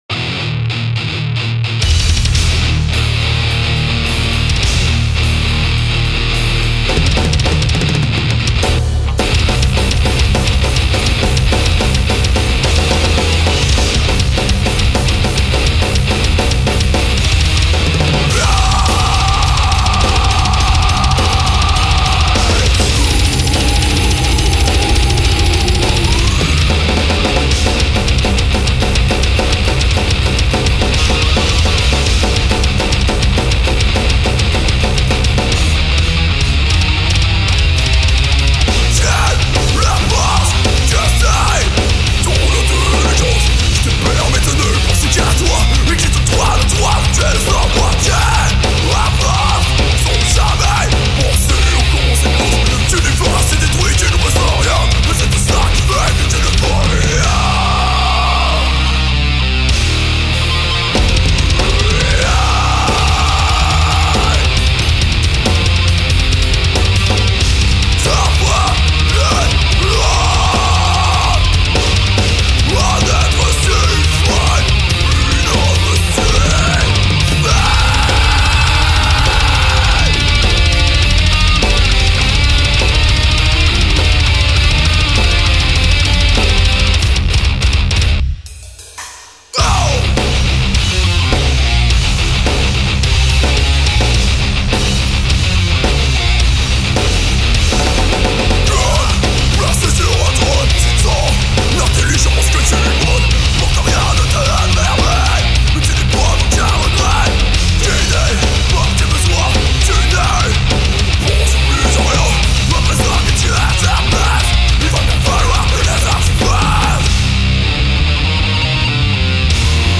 metalcore